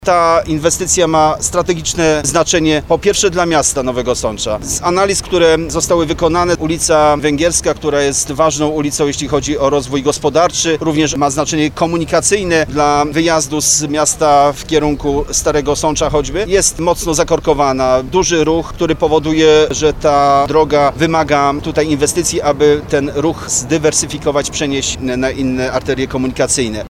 Wiceminister infrastruktury Stanisław Bukowiec przekazał w piątek (11.07) informację o przyznaniu rządowego dofinansowania.